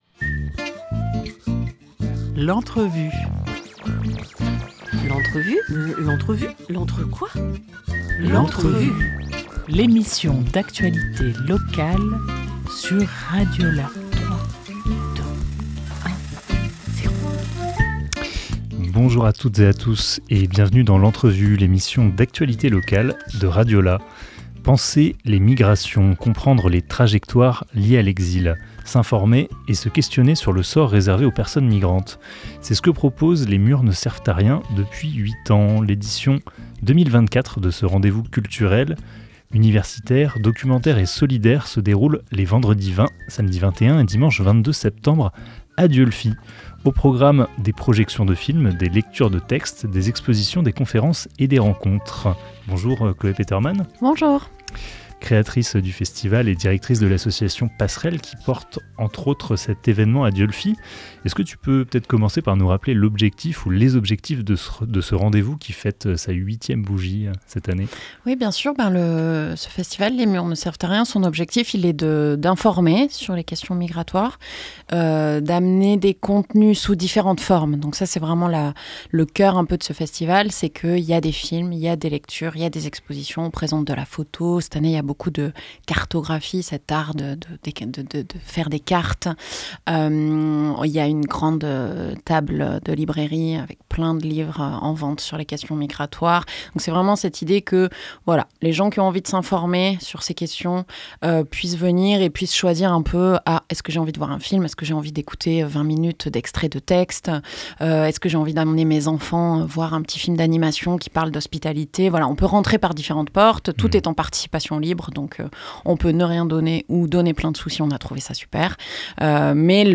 5 septembre 2024 11:45 | Interview